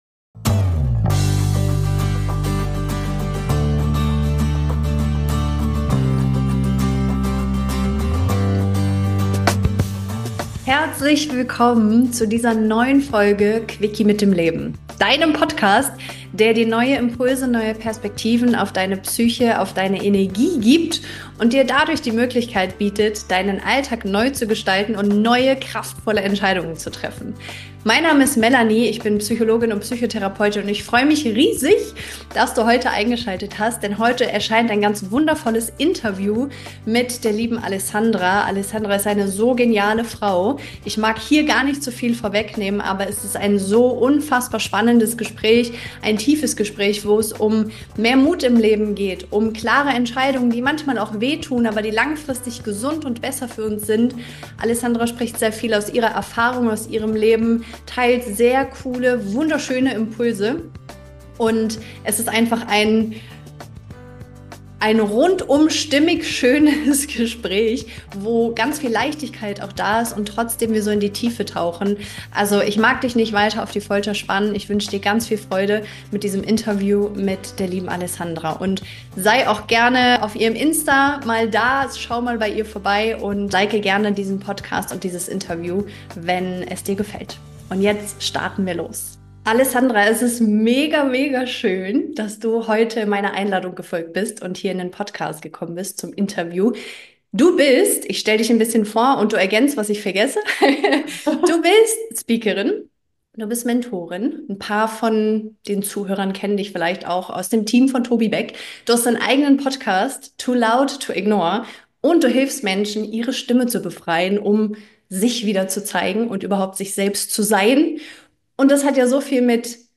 Ein Gespräch voller Kraft, Tiefe und Befreiung.